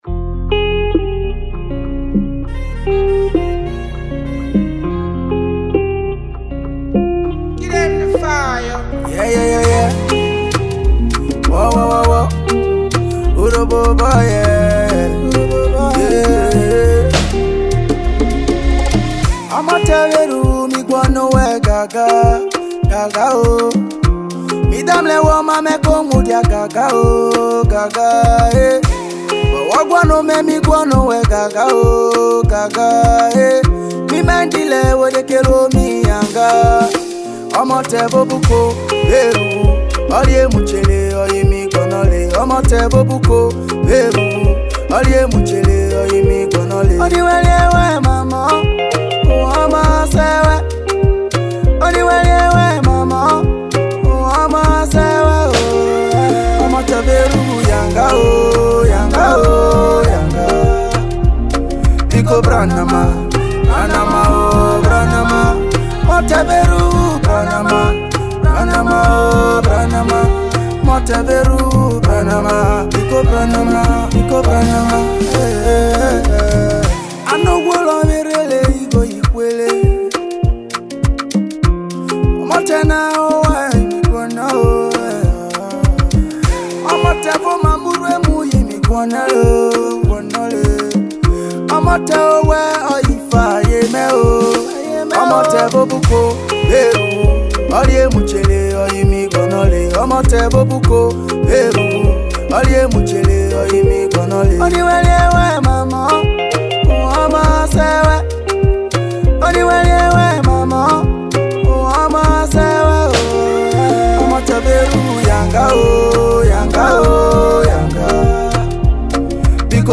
Nigerian talented singer and songwriter
Urhobo tiktok viral sound
Urhobo song